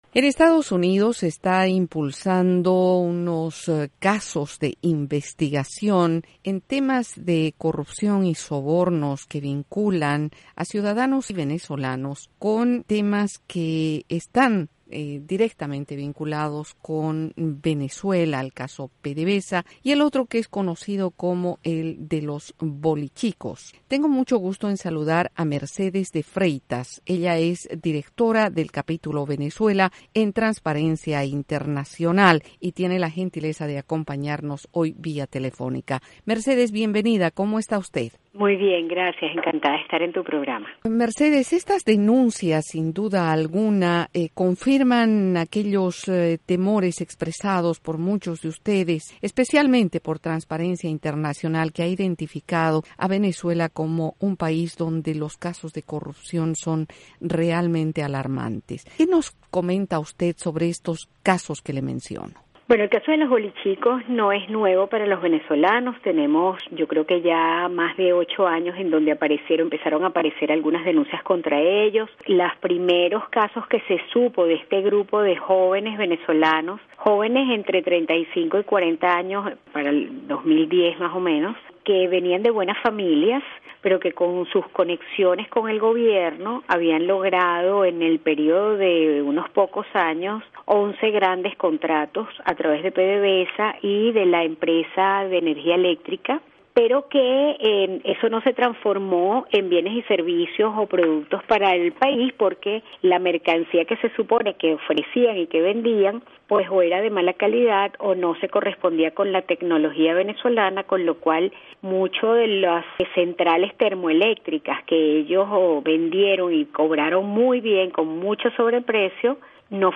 Colaboración con entrevista